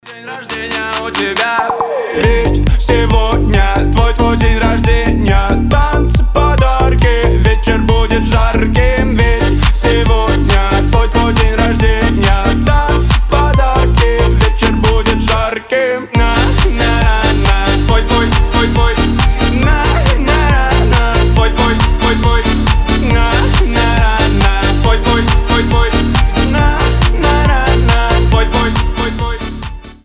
веселые и позитивные мелодии!
Танцевальные рингтоны
Аккордеон
Поп